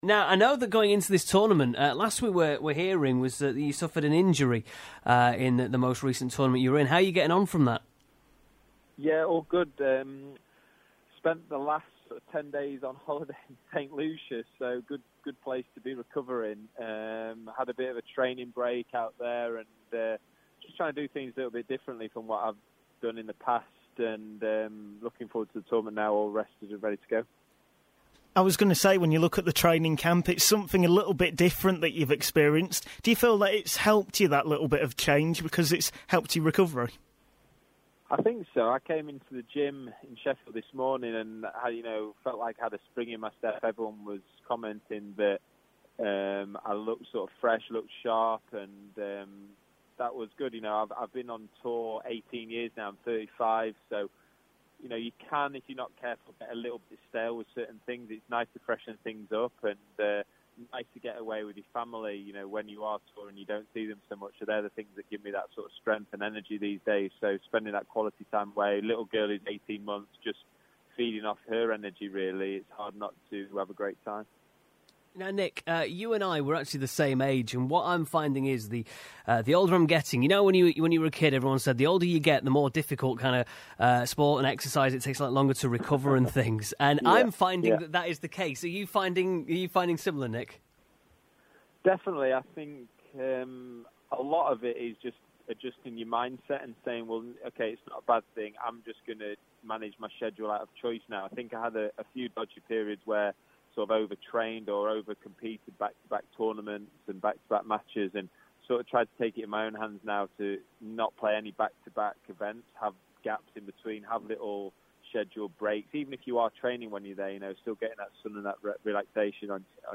Squash player Nick Matthew previews upcoming Allam British Open